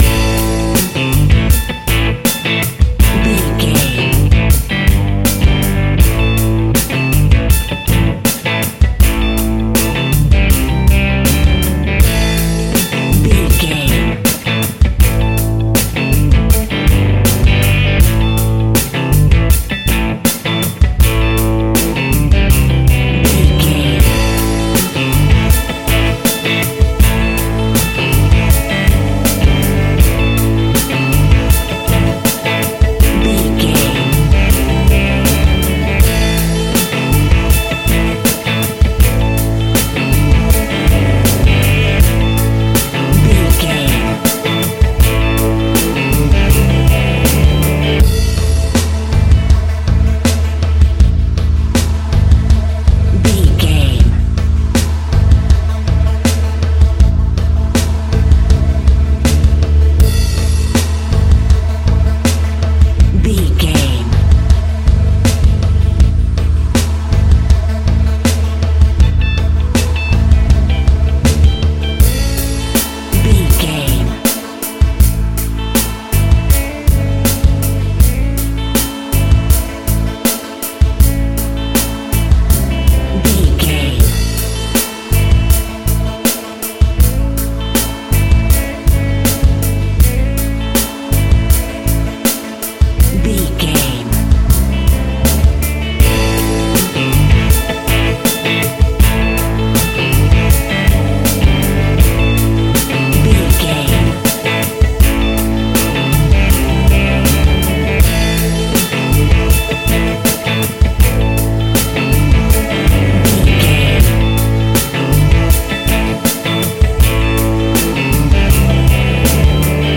Rock Pop Background Music Theme.
Uplifting
Aeolian/Minor
Slow
distortion
Instrumental rock
drums
bass guitar
electric guitar
piano
hammond organ